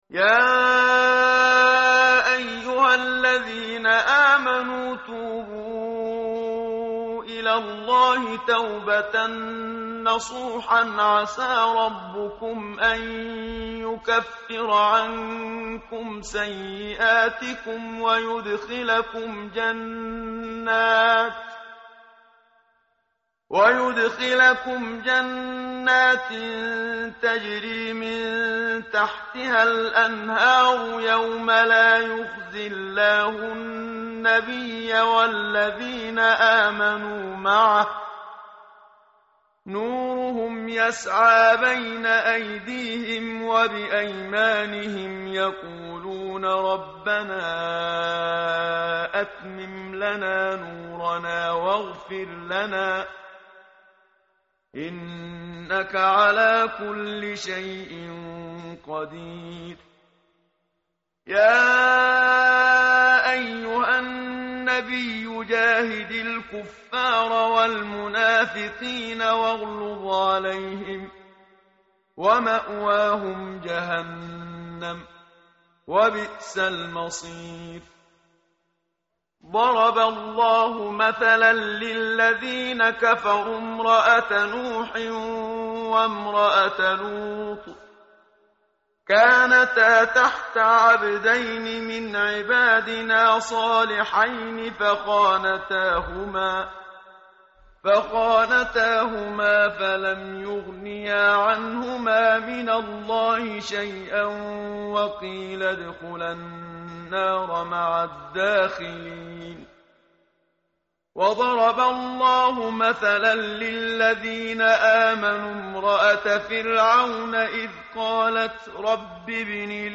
متن قرآن همراه باتلاوت قرآن و ترجمه
tartil_menshavi_page_561.mp3